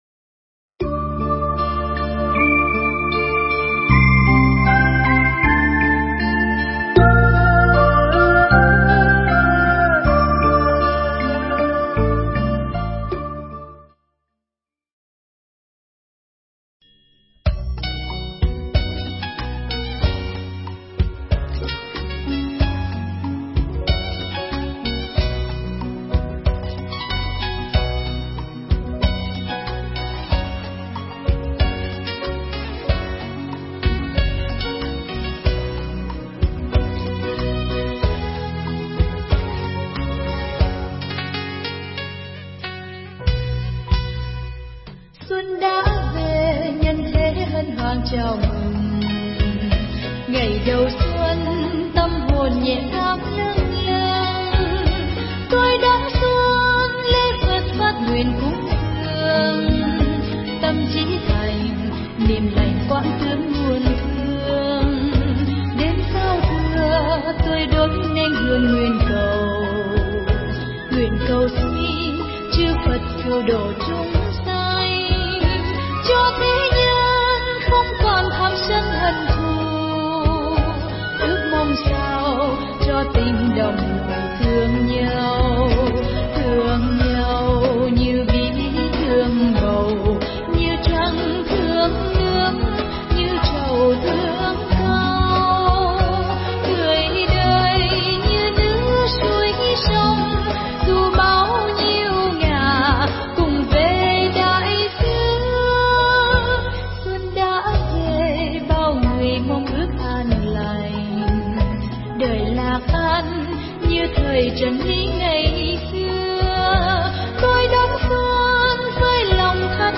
Nghe Mp3 thuyết pháp Dân Hòa Nước Mạnh
Nghe mp3 pháp thoại Dân Hòa Nước Mạnh